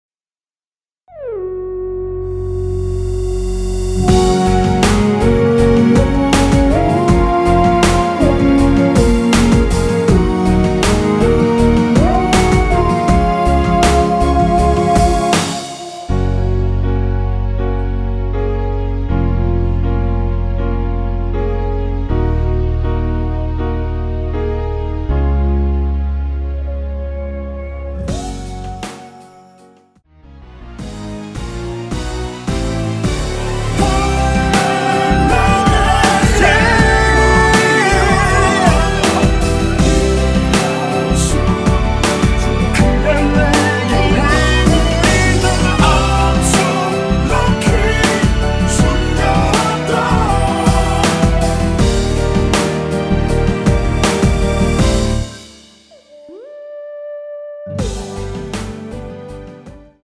원키에서 (-3)내린 코러스 포함된 MR입니다.
Gb
앞부분30초, 뒷부분30초씩 편집해서 올려 드리고 있습니다.
중간에 음이 끈어지고 다시 나오는 이유는